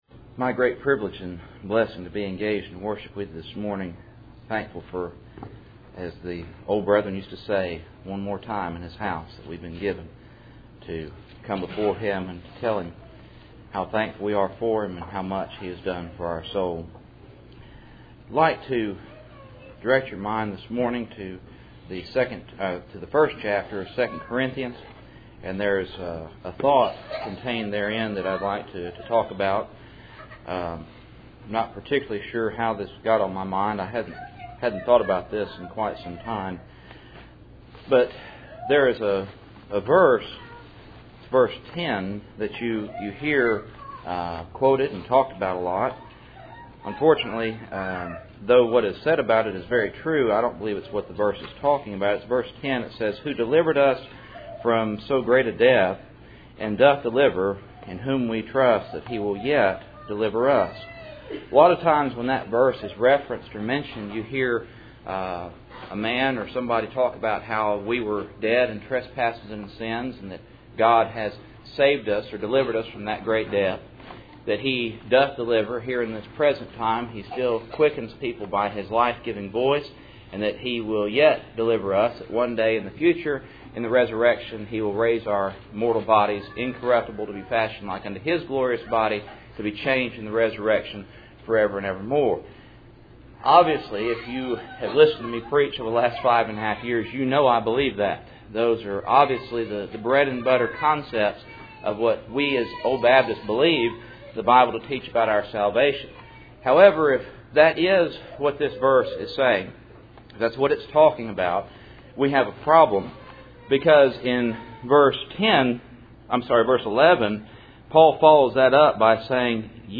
Passage: 2 Corinthians 1:8-11 Service Type: Cool Springs PBC Sunday Morning %todo_render% « Mary